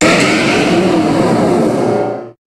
Cri de Méga-Métalosse dans Pokémon HOME.
Cri_0376_Méga_HOME.ogg